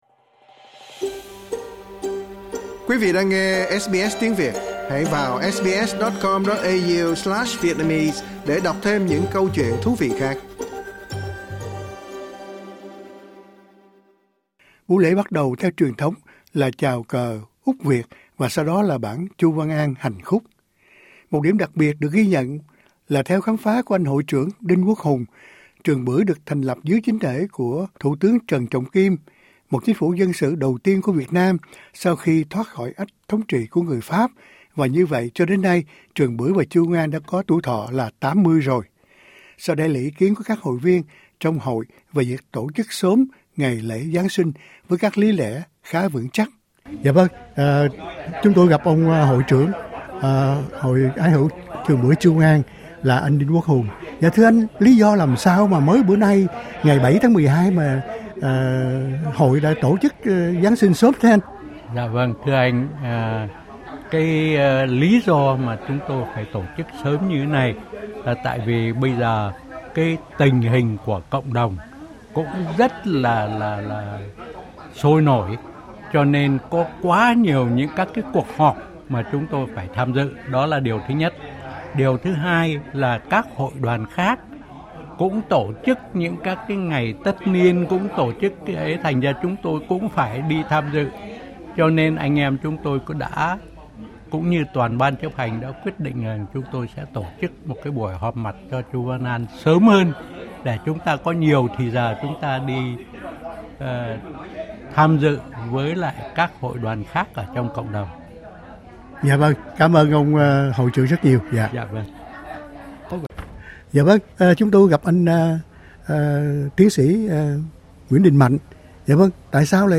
Mời quí vị theo chân chúng tôi đến tham dự một trong những buổi lễ sớm nầy.